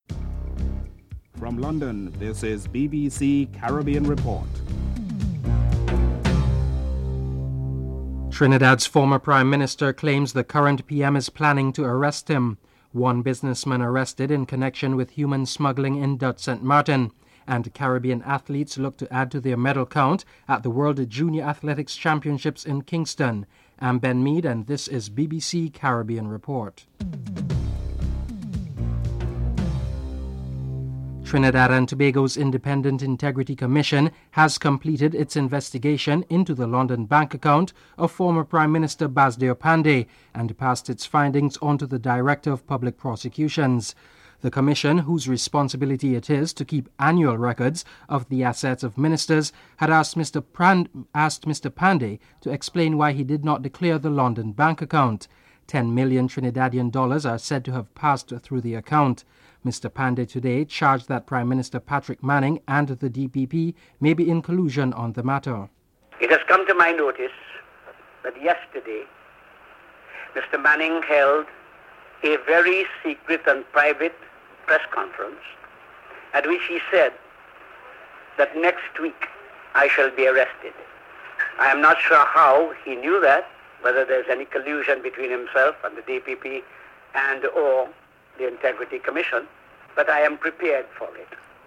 The British Broadcasting Corporation
1. Headlines: (00:00-00:27)